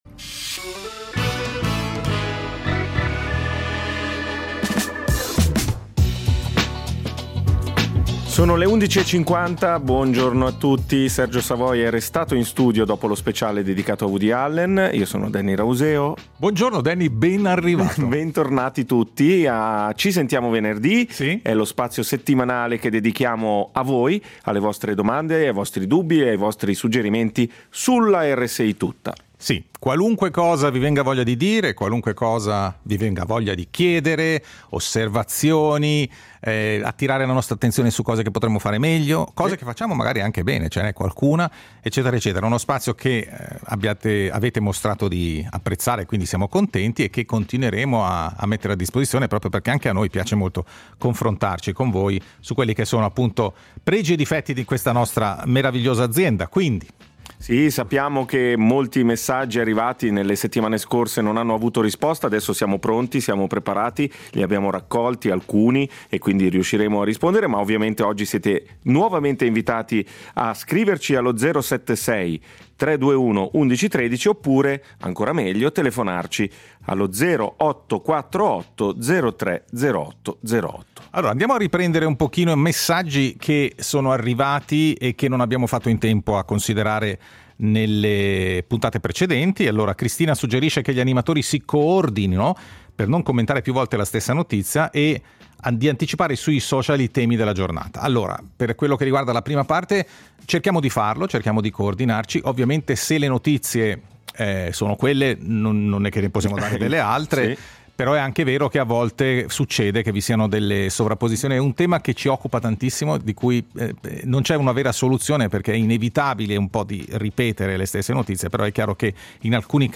Insomma, “Ci sentiamo venerdì” è il tavolo radiofonico dove ci si parla e ci si ascolta.